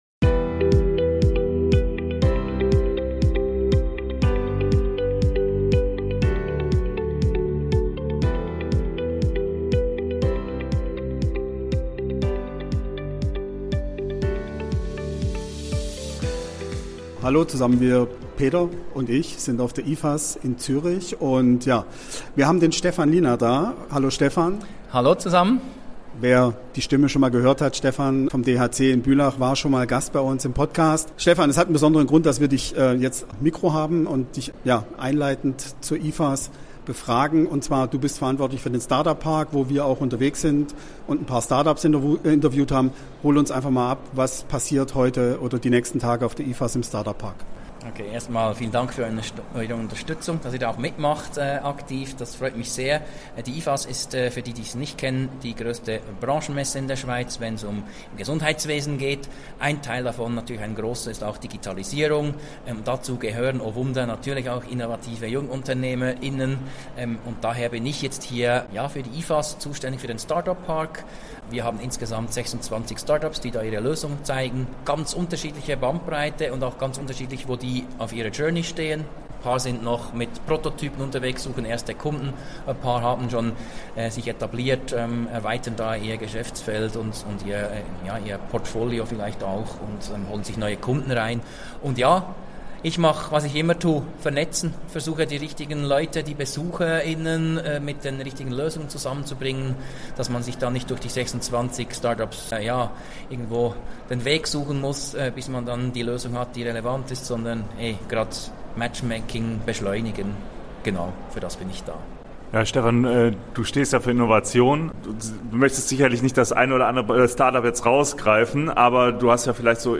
Wir sind mit Rocketing Healthcare unterwegs auf der IFAS 2024 in Zürich.